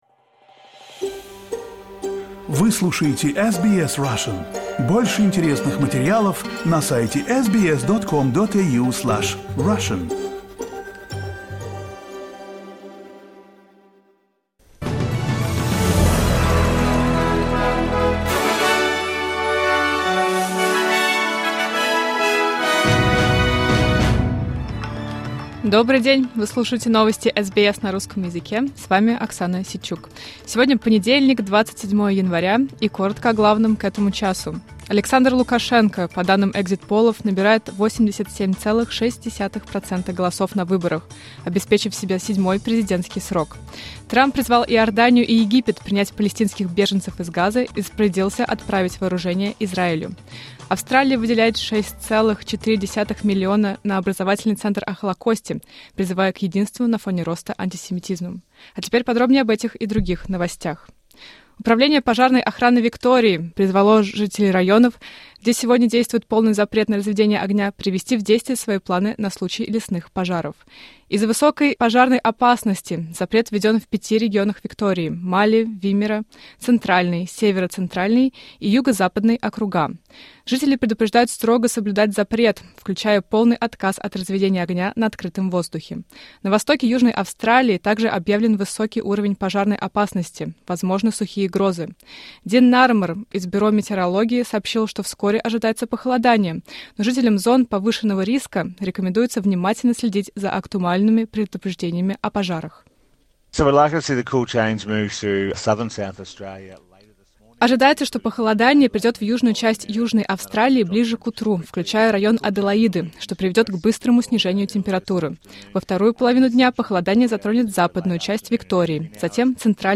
Новости SBS на русском языке — 27.01.2025